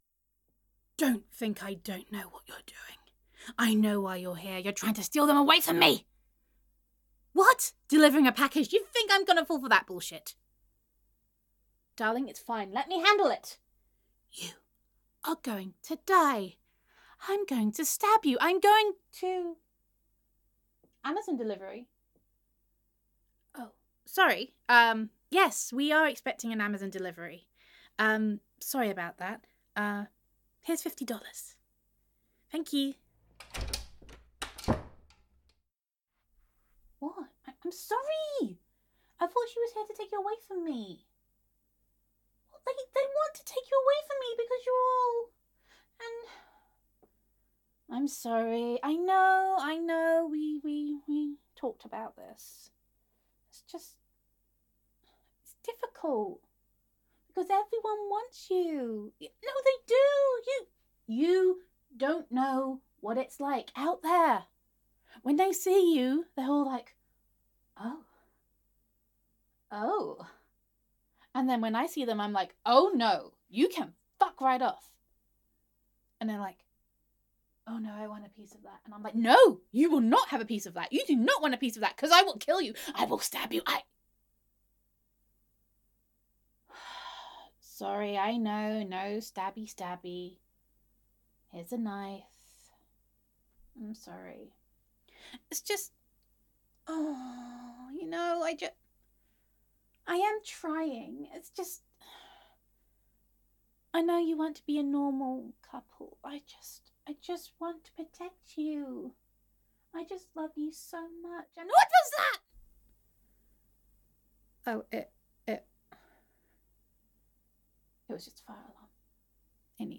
[F4A] Your Reformed Yandere Girlfriend and the Amazon Delivery